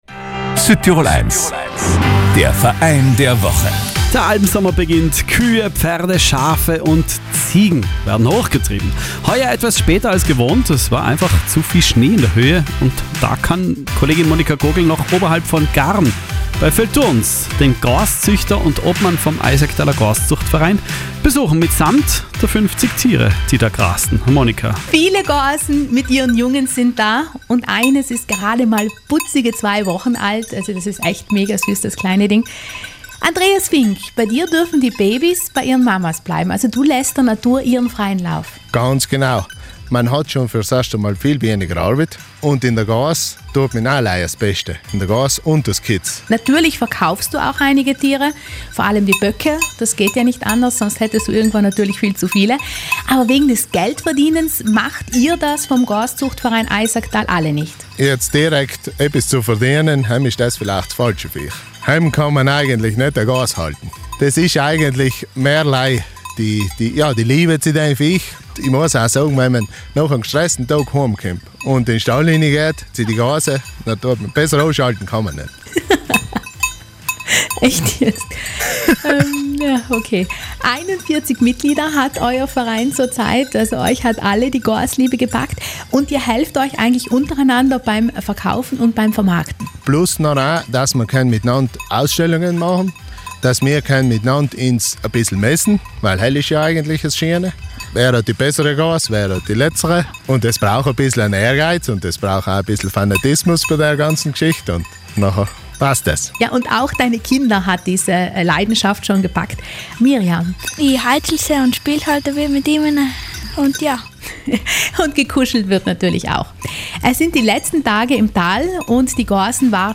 Auch in Südtirol kommen immer mehr Menschen auf die „Goas“. 41 Mitglieder hat der Eisacktaler Goaszuchtverein. Wie sehr Südtirols Almen die Tiere brauchen und welche Abenteuer die Züchter mit den „Goasen" erleben, haben sie uns erzählt.